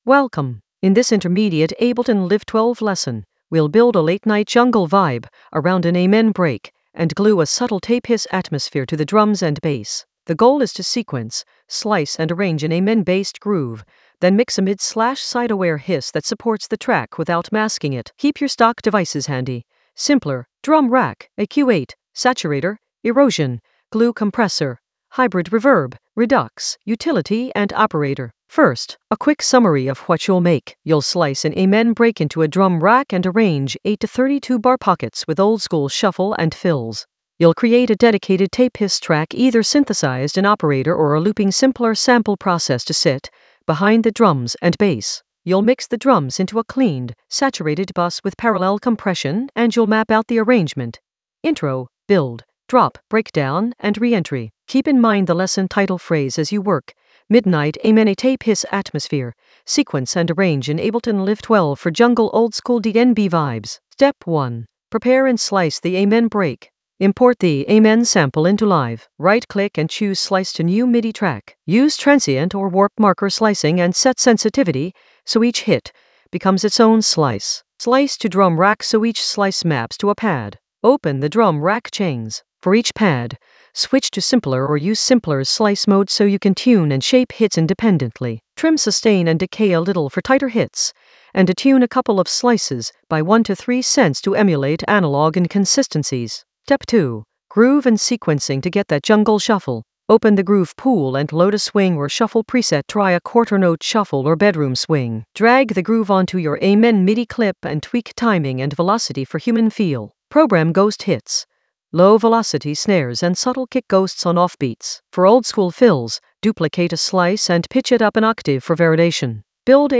An AI-generated intermediate Ableton lesson focused on Midnight Amen a tape-hiss atmosphere: sequence and arrange in Ableton Live 12 for jungle oldskool DnB vibes in the Mixing area of drum and bass production.
Narrated lesson audio
The voice track includes the tutorial plus extra teacher commentary.